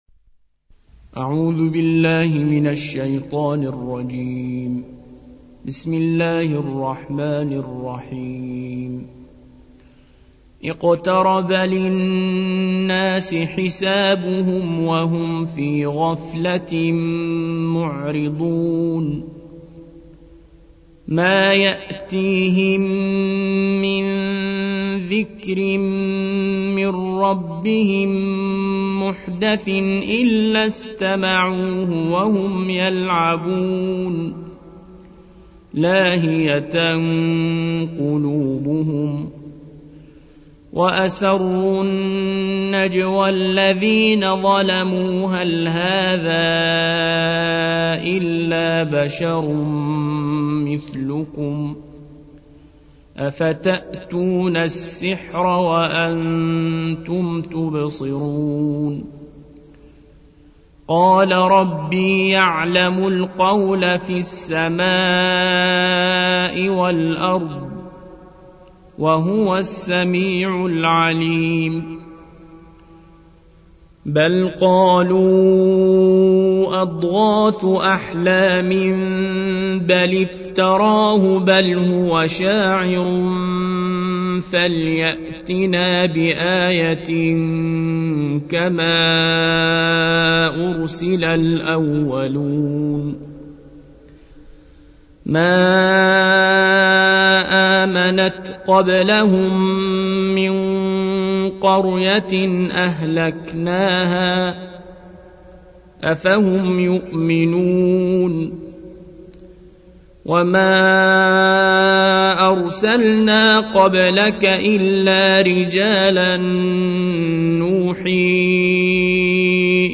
الجزء السابع عشر / القارئ